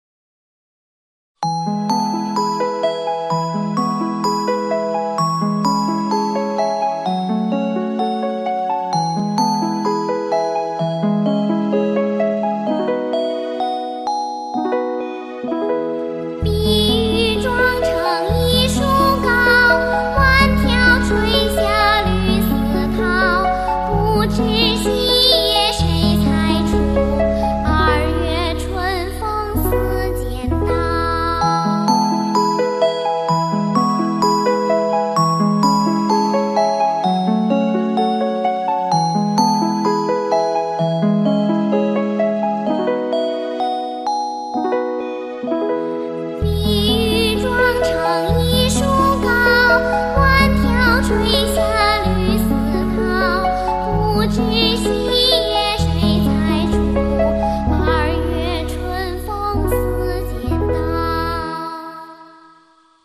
介绍： 古诗新唱